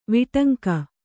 ♪ viṭanka